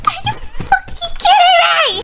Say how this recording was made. Illgetyou.wav